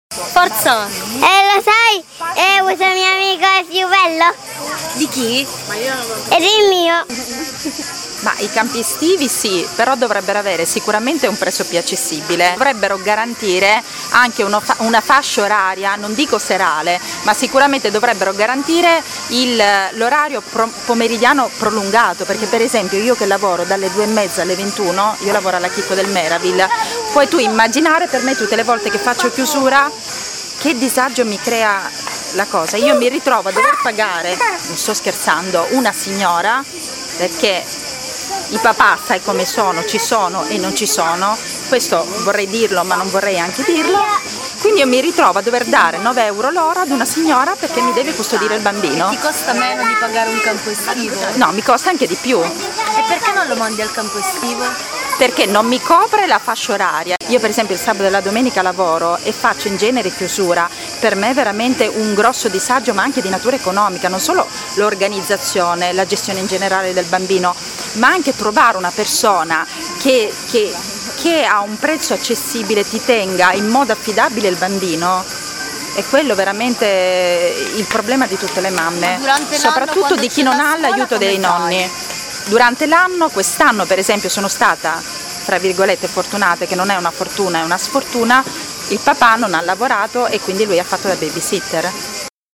Abbiamo fatto un giro nei parchi di Bologna alla ricerca di mamme con pargoli e abbiamo chiesto ad alcune di loro un’opinione sui campi estivi.